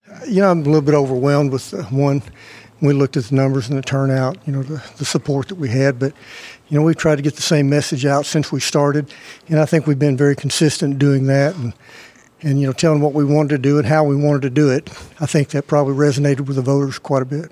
Hollingsworth Live on KTLO following Tuesday’s victory
KTLO, Classic Hits and The Boot News spoke with Sheriff elect Hollingsworth live at the Baxter County Courthouse Tuesday evening following the news of his victory.